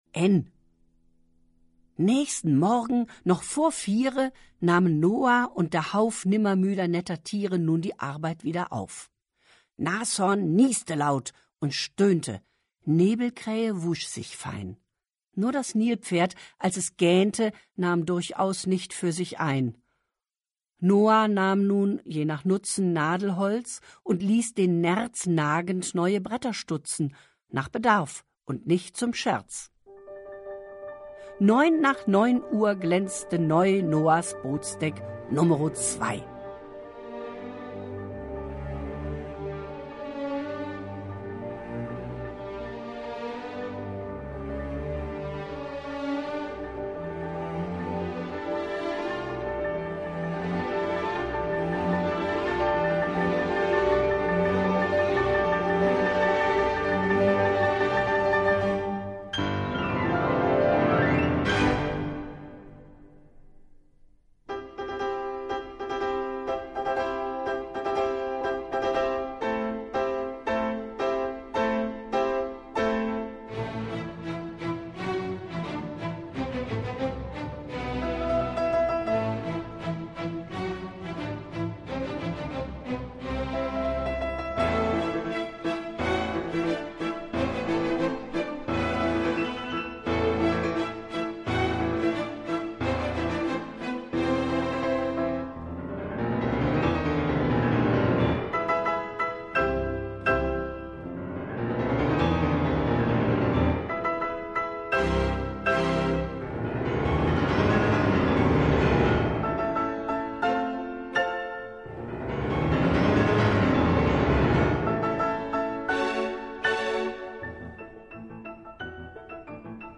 Elke Heidenreich (Sprecher)
Schlagworte ABC; Bilderbuch • ABC; Bilderbuch (Audio-CDs) • Arche Noah; Bilderbuch • Arche Noah; Bilderbuch (Audio-CDs) • Bibel • Buchstaben • Hörbuch • Hörbuch für Kinder/Jugendliche • Hörbuch für Kinder/Jugendliche (Audio-CD) • Hörbuch; Lesung für Kinder/Jugendliche • Lesung • Lesung mit Musik • Reime • Sprachspiele